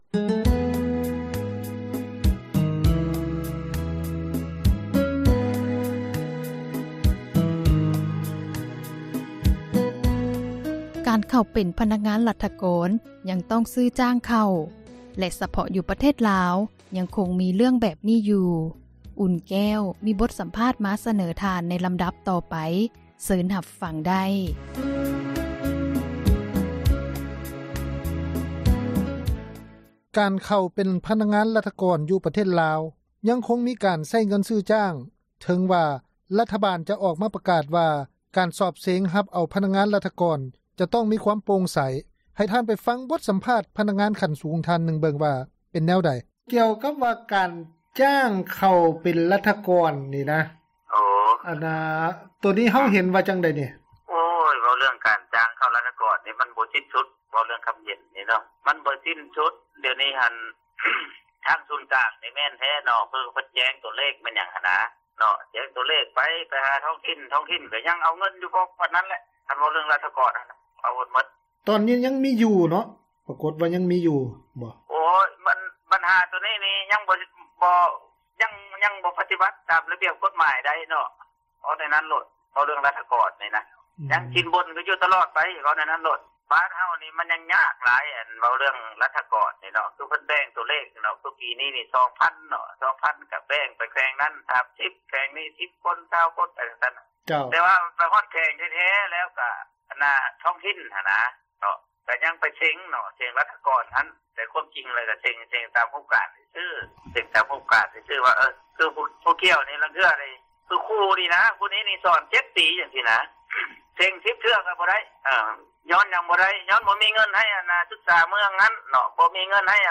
ເຊີນທ່ານ ຟັງການສຳພາດ ພະນັກງານ ຂັ້ນສູງຢູ່ລາວ, ແລະ ຟັງຈາກປະຊາຊົນລາວ ທັມມະດາສາມັນ ເບິ່ງວ່າຂະເຈົ້າຮູ້ສຶກແນວໃດ.